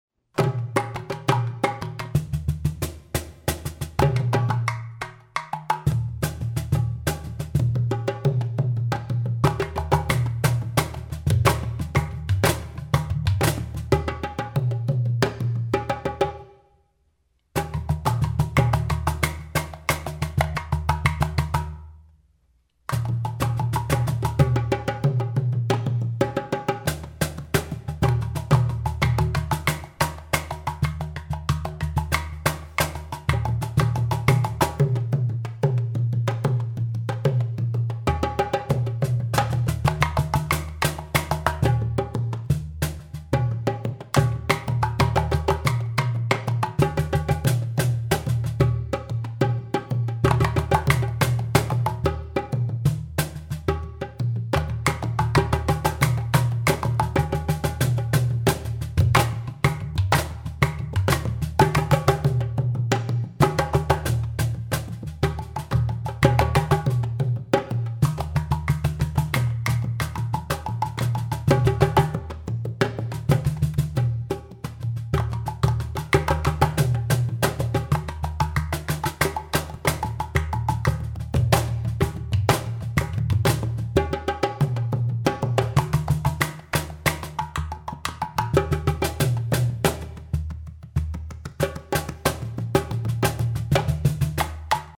Cajon, udu-boo, dholak